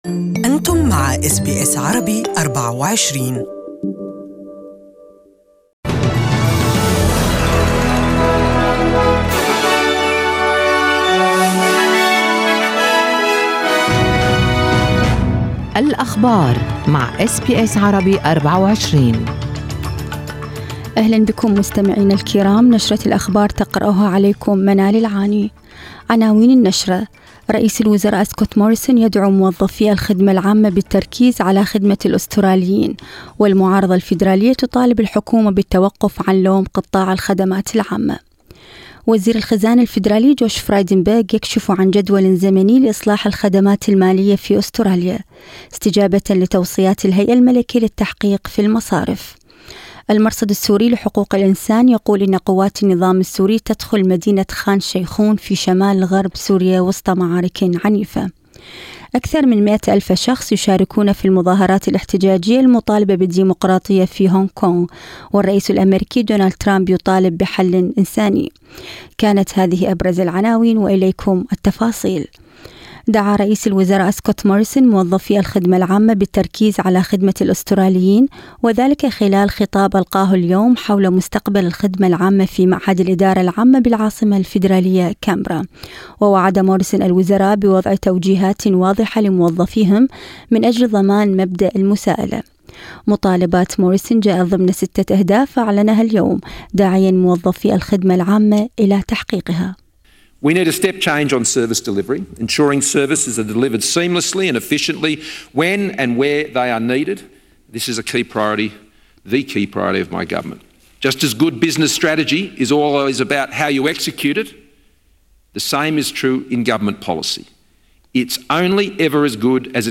أخبار المساء: الحكومة تعلن خطة لإصلاح البنوك تنفذ بنهاية العام المقبل
يمكن الاستماع لنشرة الأخبار المفصلة باللغة العربية في التقرير الصوتي أعلاه.